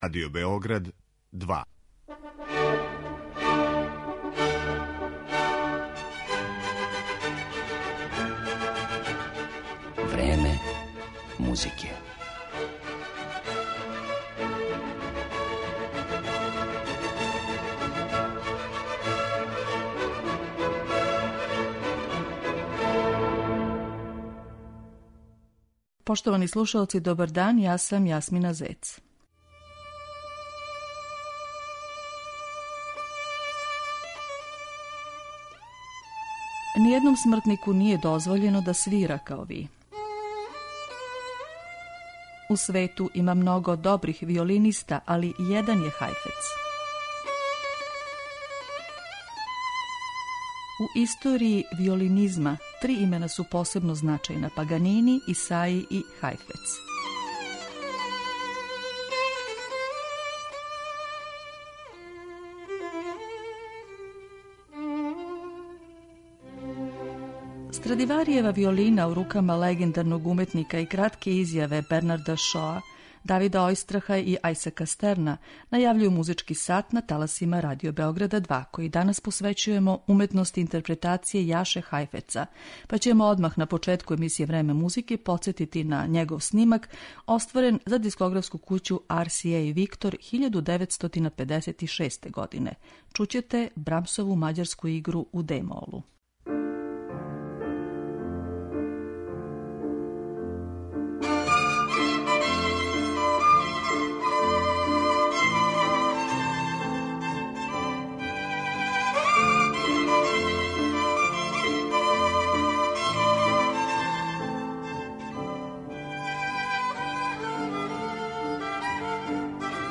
архивске снимке
виолинисте